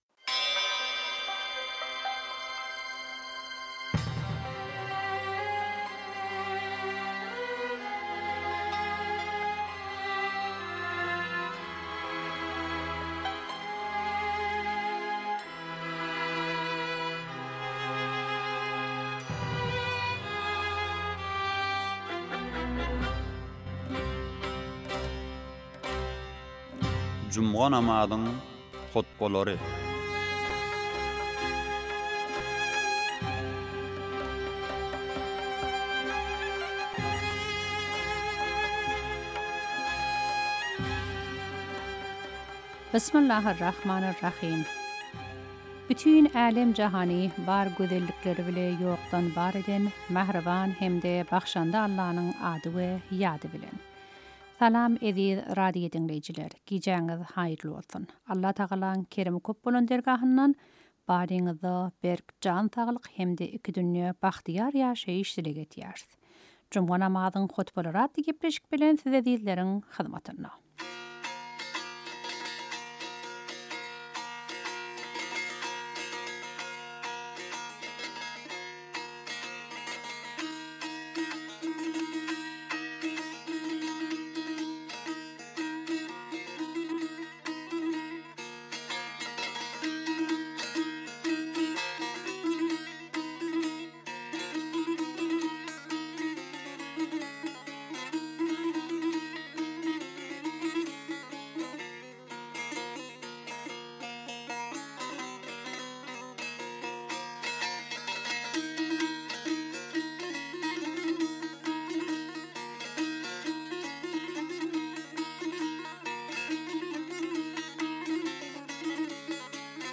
juma namazyň hutbalary
Tehraniň juma namazy